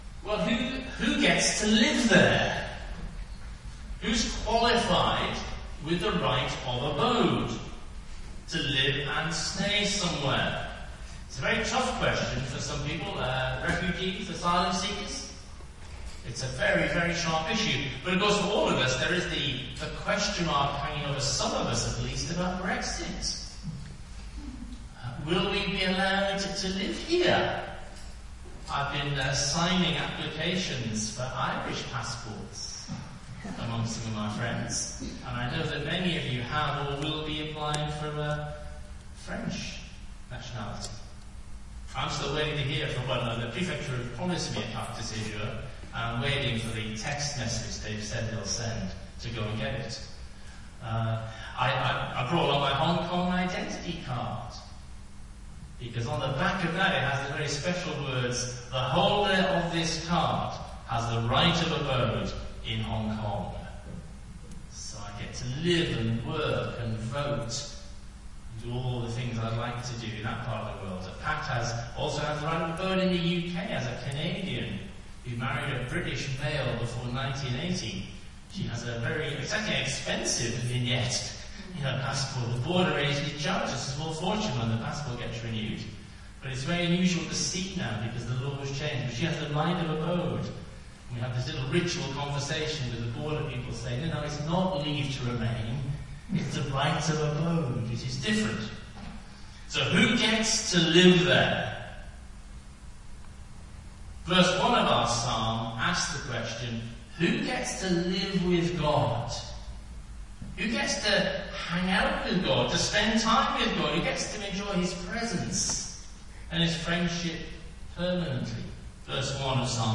Sermons – Page 20 – St Marks Versailles